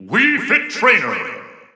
The announcer saying Wii Fit Trainer's name in English and Japanese releases of Super Smash Bros. 4 and Super Smash Bros. Ultimate.
Wii_Fit_Trainer_English_Announcer_SSB4-SSBU.wav